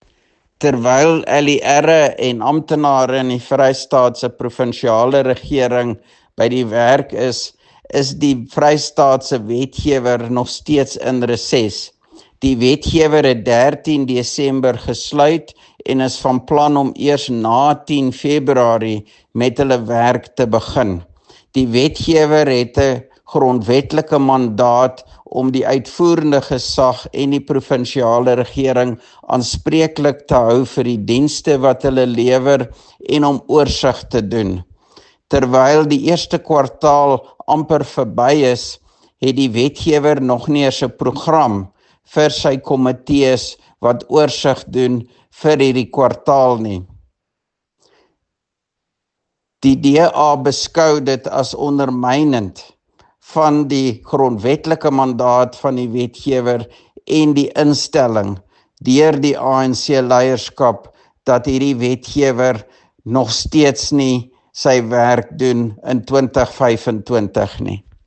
Afrikaans soundbites by Roy Jankielsohn MPL